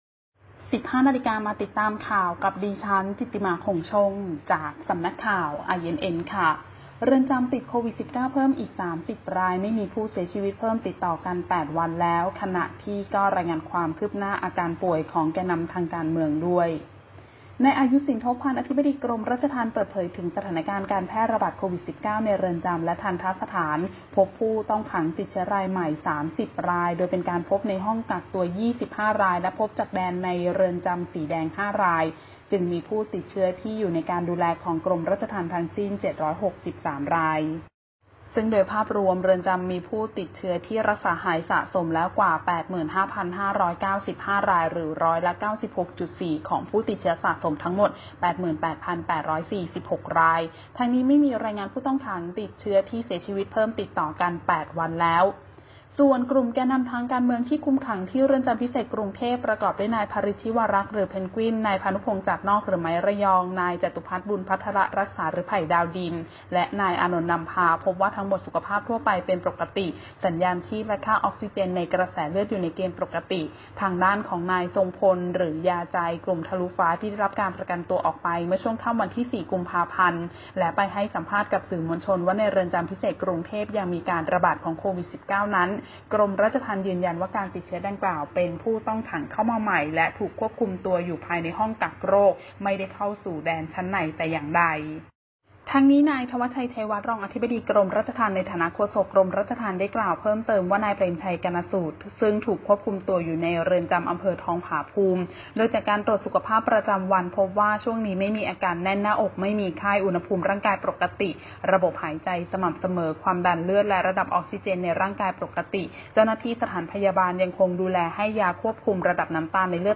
ข่าวต้นชั่วโมง 15.00 น.
Video คลิปข่าวต้นชั่วโมง ข่าว